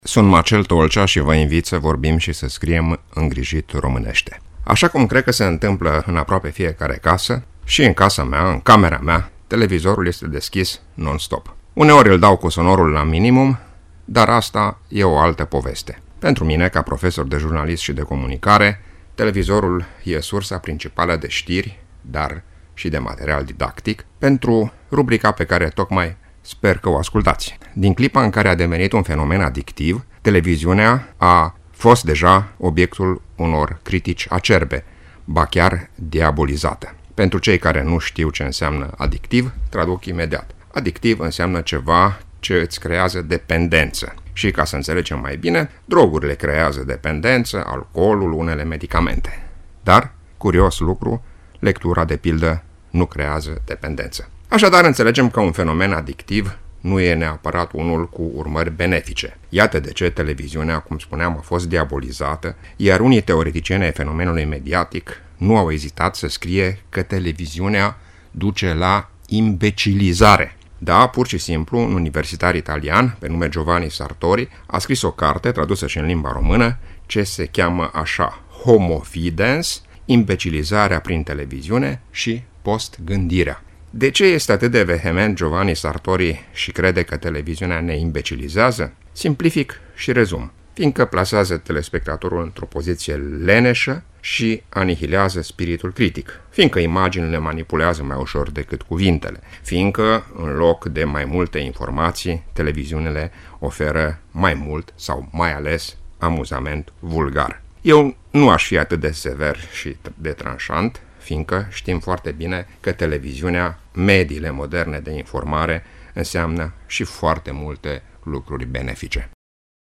(rubrică difuzată în 18 iulie 2017)